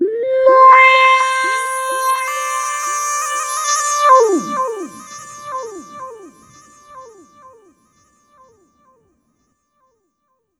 VOCODE FX2-R.wav